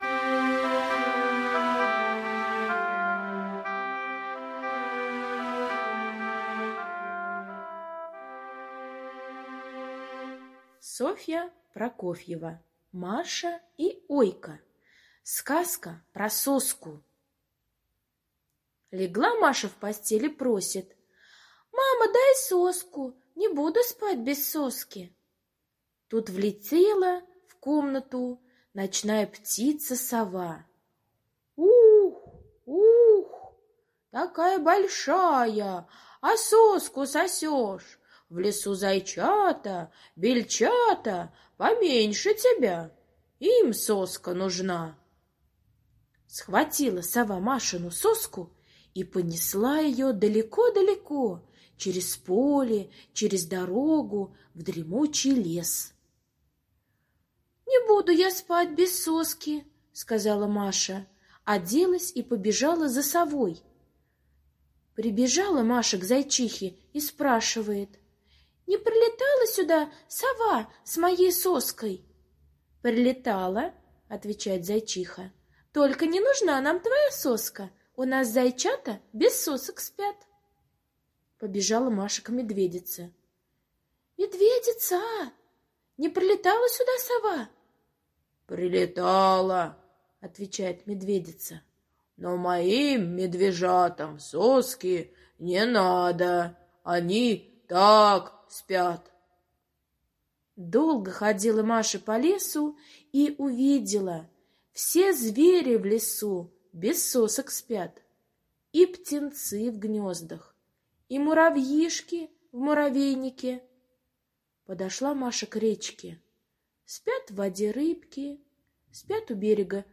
Сказка про соску - аудиосказка Прокофьевой С. Сказка о том, как Сова унесла в лес машину соску, а Маша пошла ее искать.